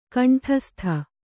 ♪ kaṇṭhastha